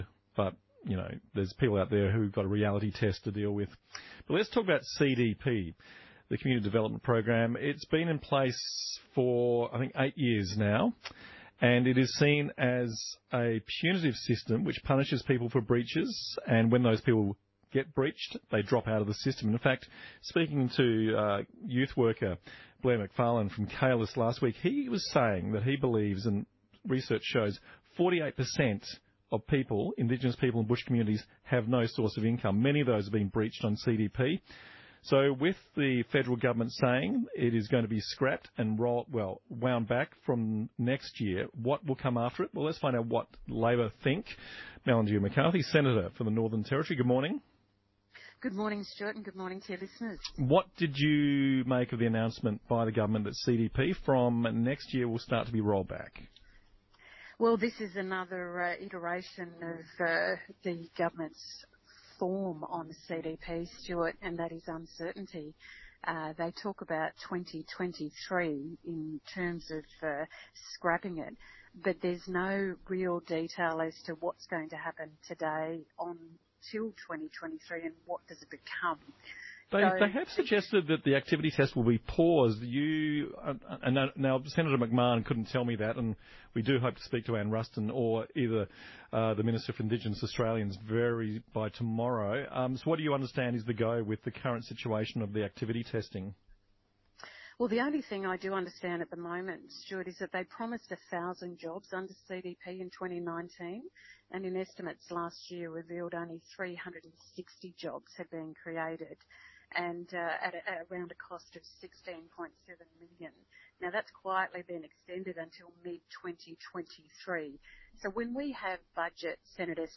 TRANSCRIPT: ABC ALICE SPRINGS, 12 MAY 2021